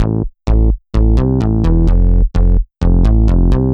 Index of /musicradar/french-house-chillout-samples/128bpm/Instruments
FHC_MunchBass_128-A.wav